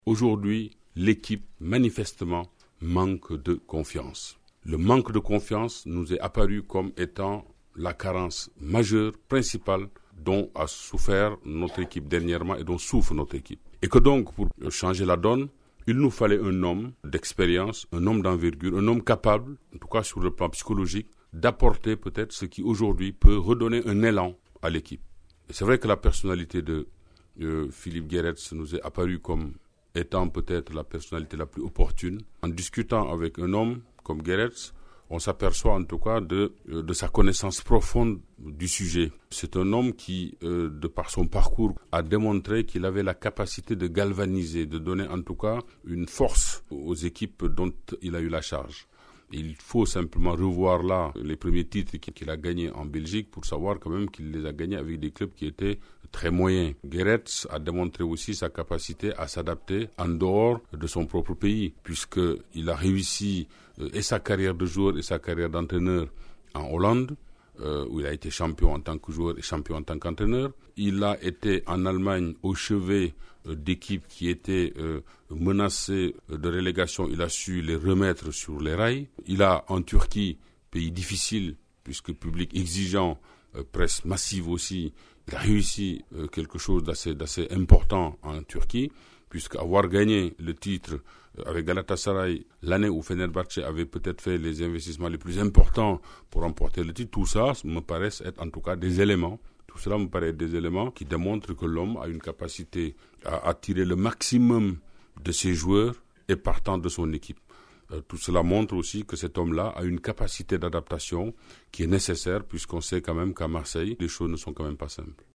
Pour ceux qui ne l’ont pas connu, parmi les plus jeunes fans de l’OM, ou les autres qui n’ont jamais entendu s’exprimer Pape Diouf dans son rôle de président de l’OM, voici quelques-unes de ses interventions parmi les plus savoureuses devant la presse à La Commanderie.